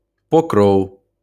Pokróv, ukrainsk udtale: [poˈkrɔu̯]  (